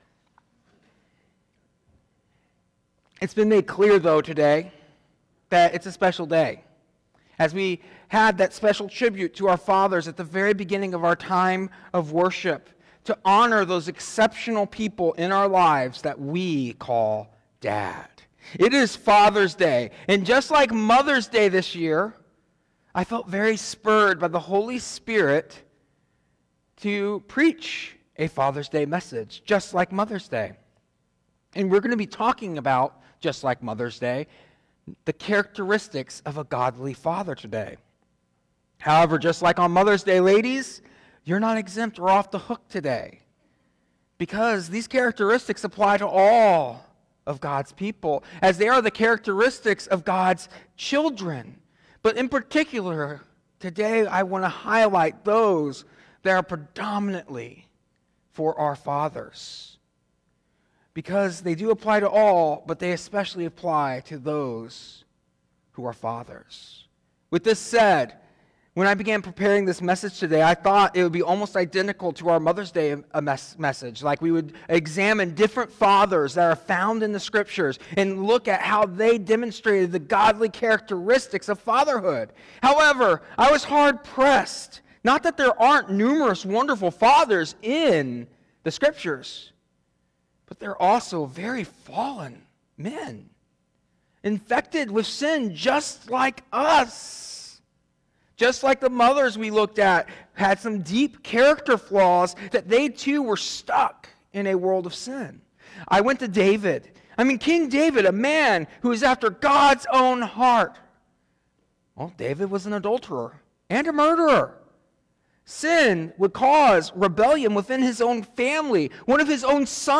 1 – What does it mean for a father to provide basic physical needs and emotional and spiritual support, as highlighted in the sermon?